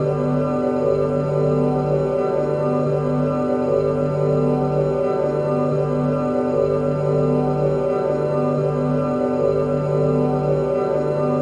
music_layer_top.mp3